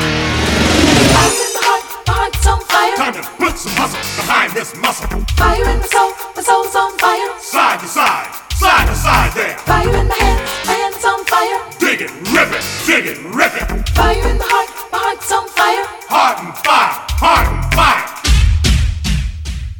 • Качество: 233, Stereo
саундтрек